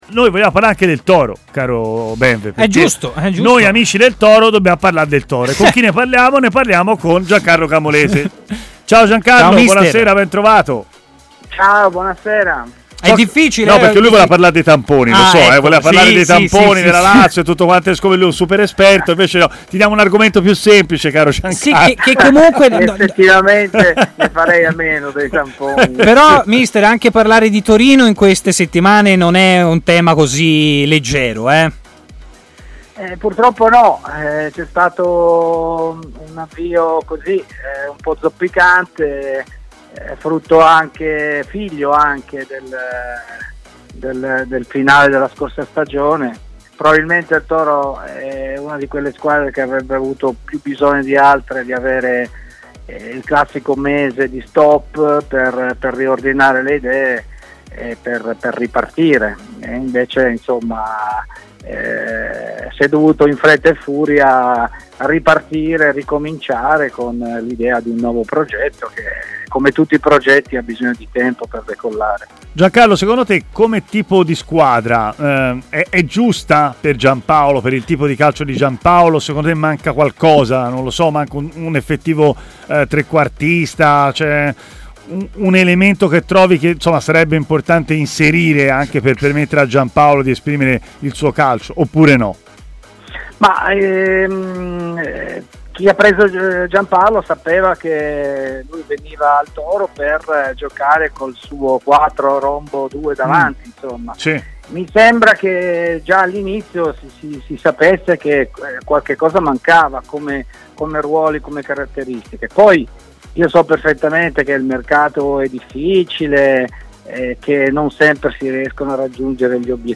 Stadio Aperto, trasmissione di TMW Radio